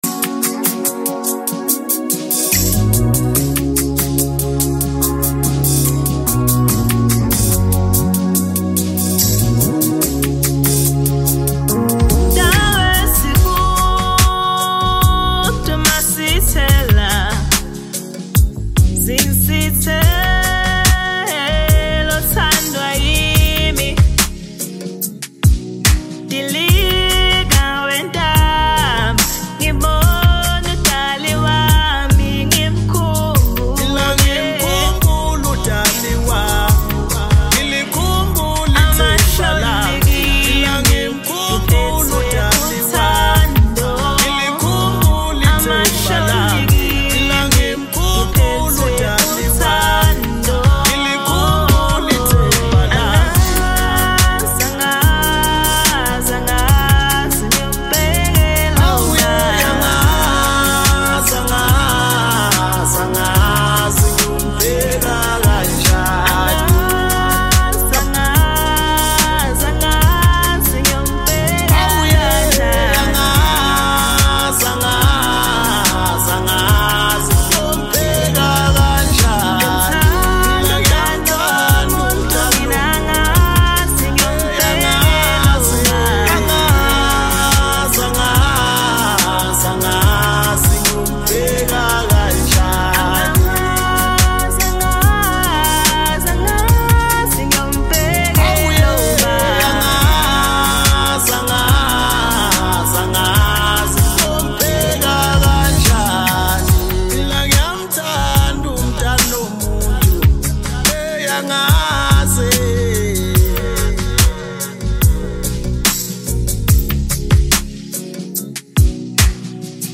Home » Maskandi » DJ Mix » Hip Hop